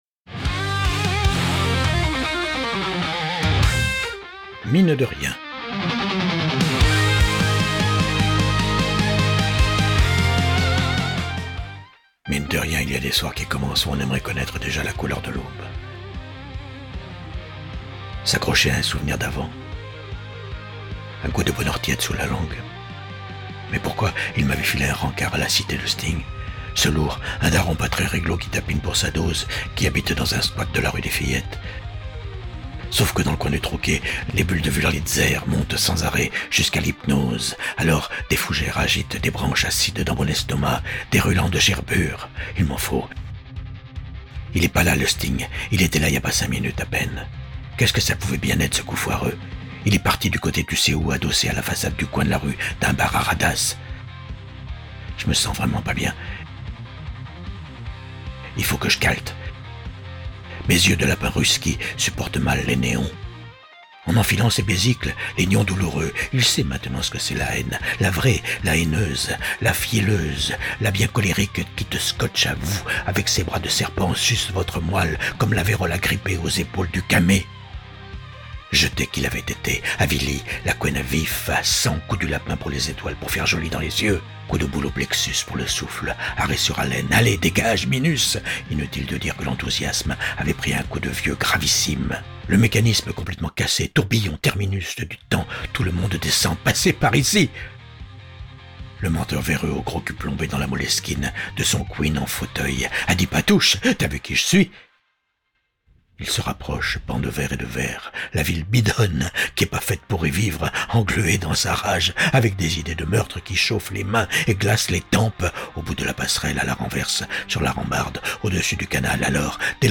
le rythme de ce texte qui pulse jusqu’à la surdose auditive